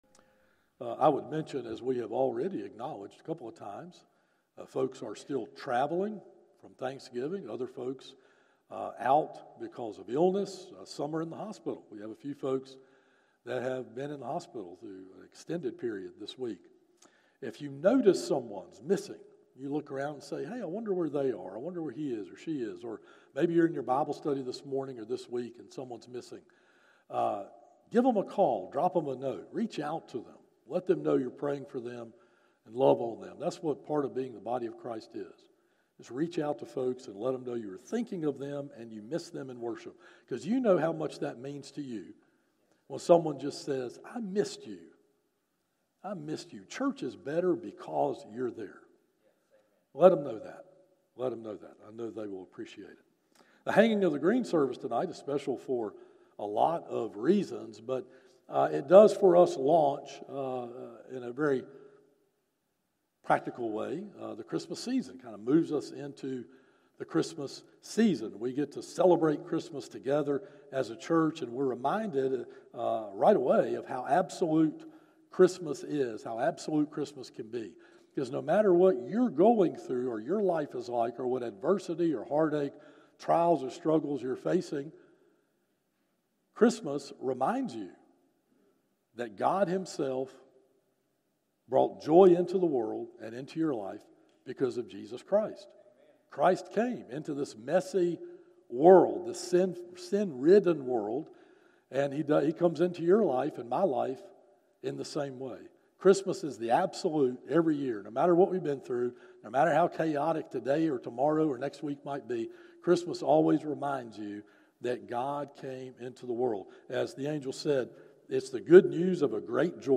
Morning Worship - 11am